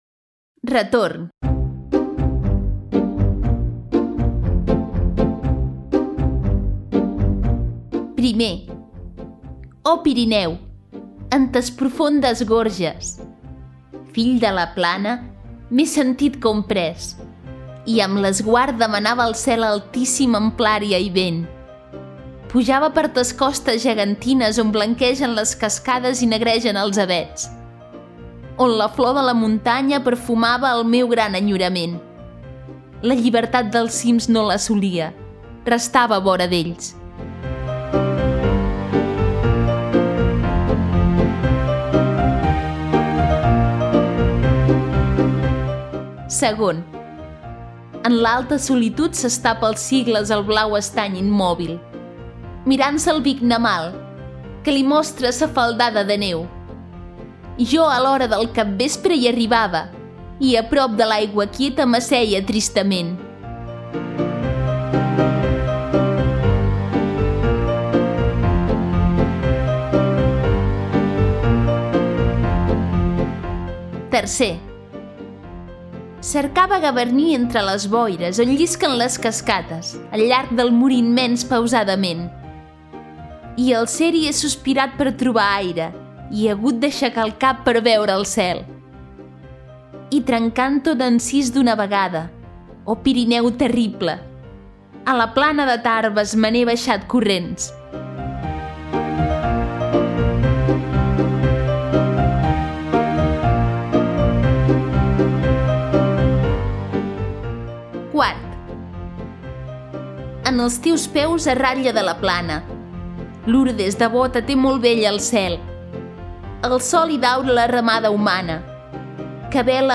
Audiollibre: Poemas (Maragall)